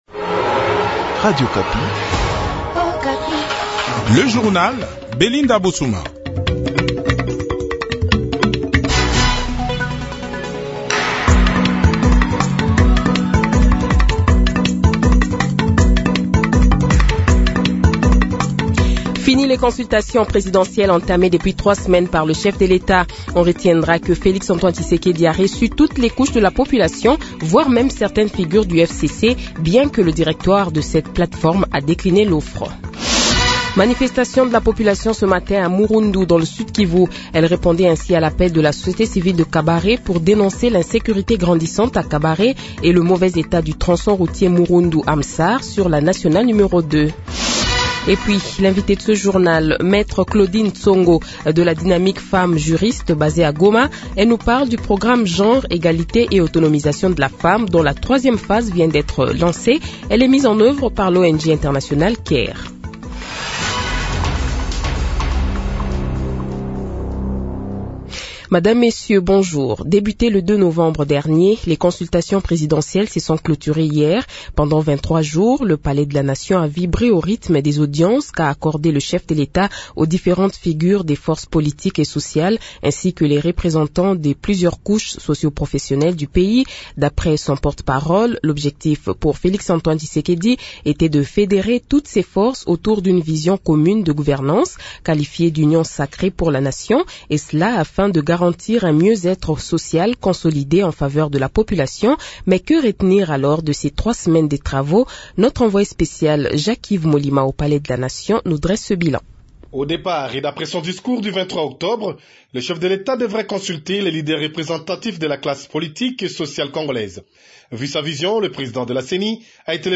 Journal Français Midi
KIN : Bilan des Consultations initiées par le chef de l’Etat GOMA : Vox pop sur les Consultations